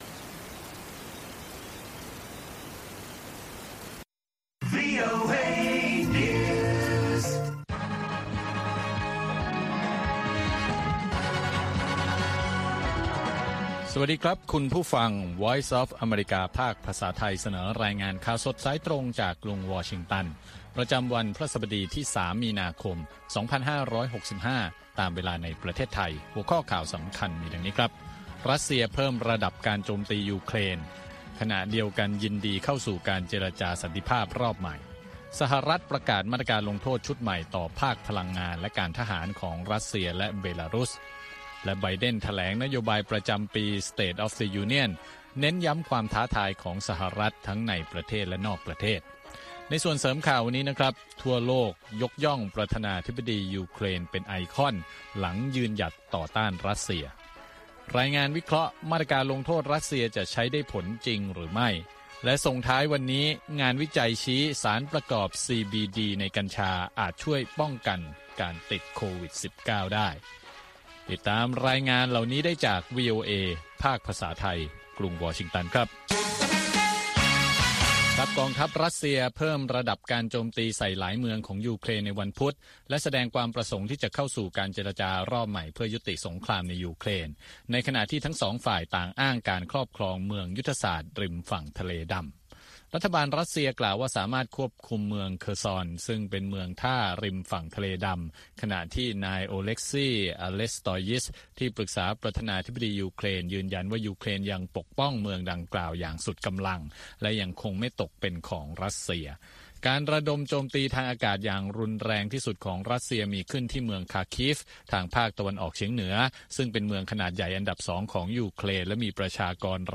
ข่าวสดสายตรงจากวีโอเอ ภาคภาษาไทย 6:30 – 7:00 น. ประจำวันพฤหัสบดีที่ 3 มีนาคม 2565 ตามเวลาในประเทศไทย